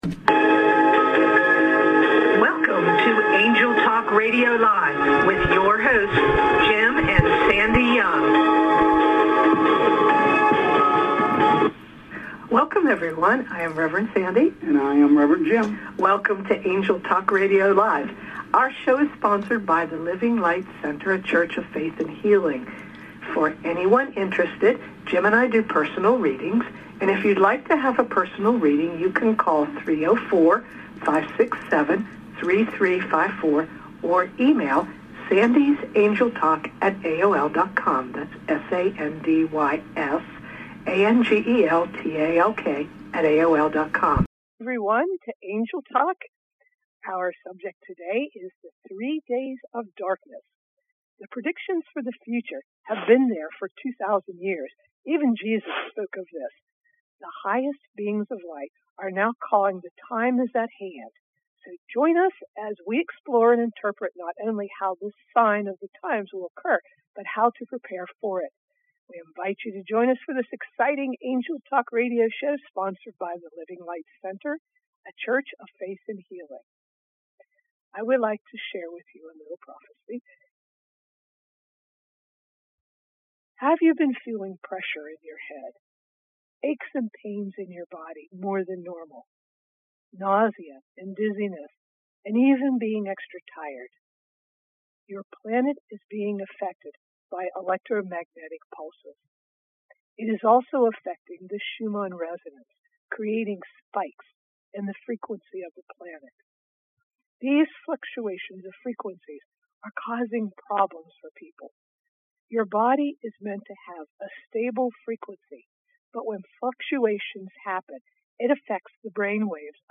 Talk Show Episode, Audio Podcast, Angel Talk and Three Days of Darkness on , show guests , about Three Days of Darkness,predictions for the future,Jesus spoke of this,highest beings of light,The time is at hand,explore and interpret,Sign of the Times,how to prepare for it, categorized as Games & Hobbies,Health & Lifestyle,Love & Relationships,Philosophy,Psychology,Religion,Inspirational,Motivational,Society and Culture